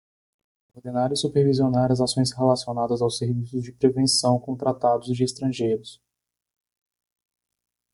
Pronounced as (IPA)
/su.peʁ.vi.zi.oˈna(ʁ)/